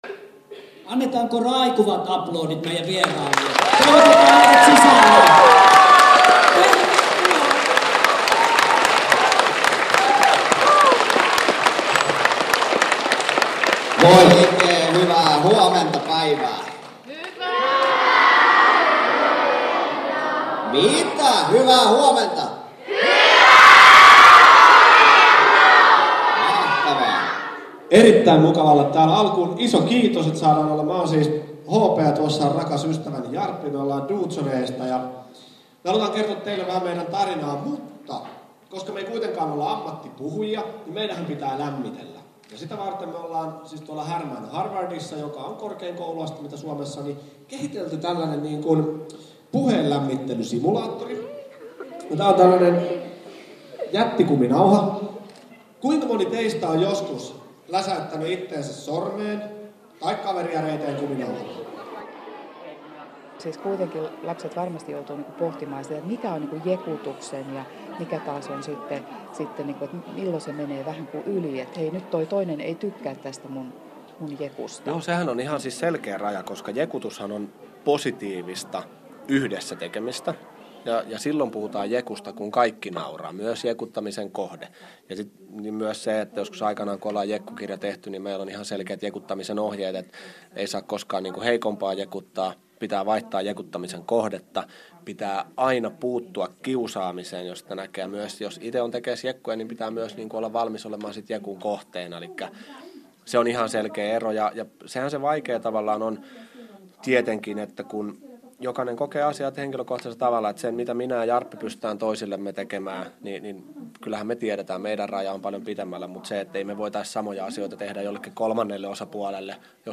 Radiohaastattelu